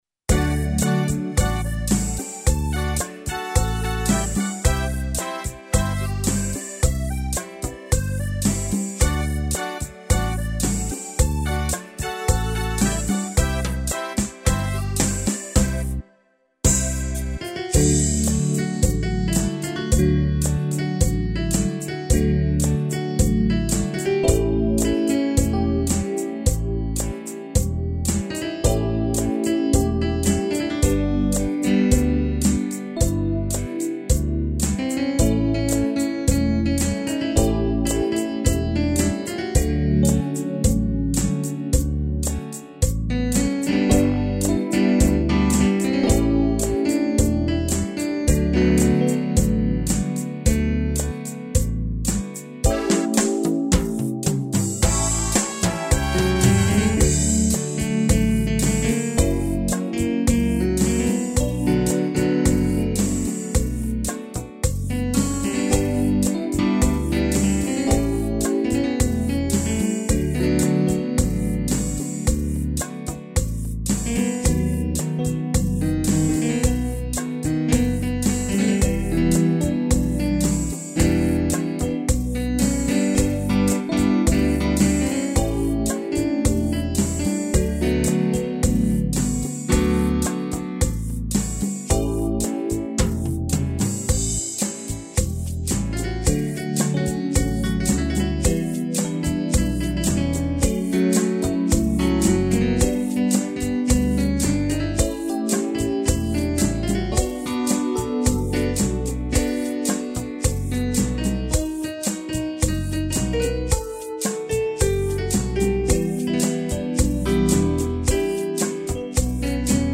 piano, acordeão, violão 7 cordas
(instrumental)